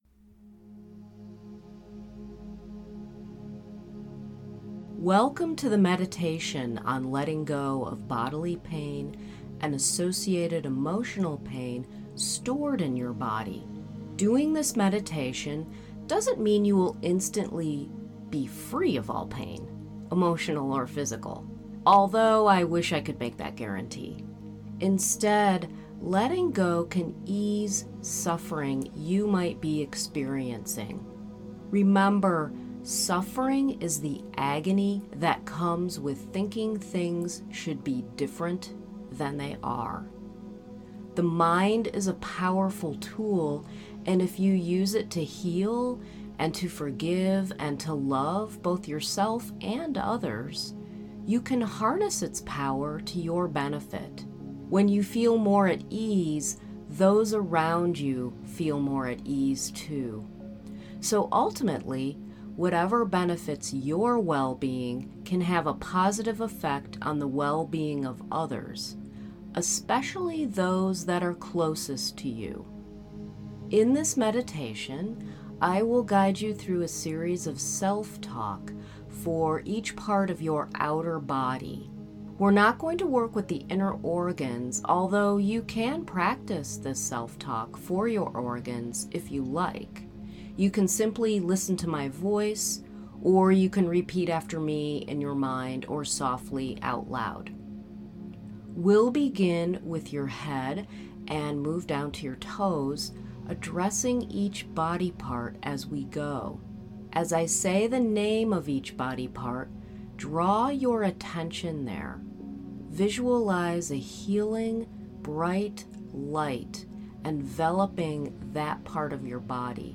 Use this meditation to ease physical pain and associated emotional memories of pain that may be stored in your body.